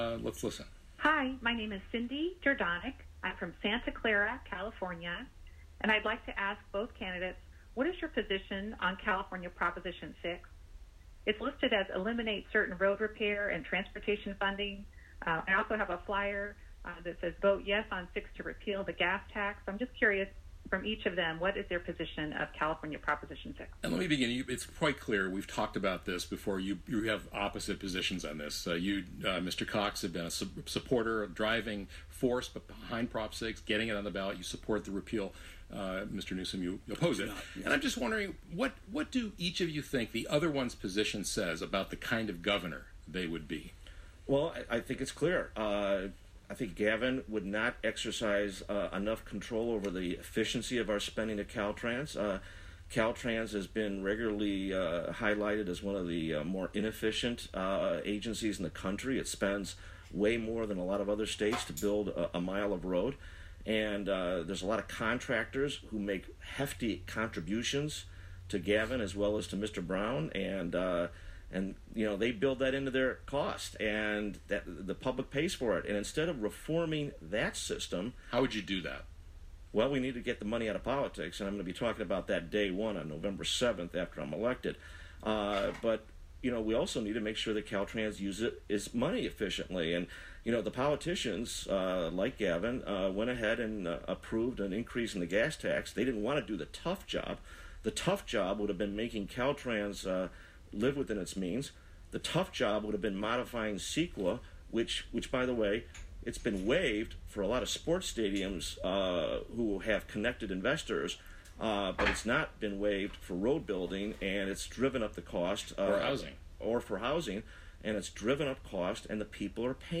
两人关于将出现在11月的选票上的六号提案（Proposition 6）决定《增加汽油税法》的命运进行辩论。
加州州长候选人民主党人纽森（Gavin Newsom）和共和党人考克斯（John Cox）8日上午10点在旧金山公共广播电台KQED展开辩论，解释为什么选民应该选他。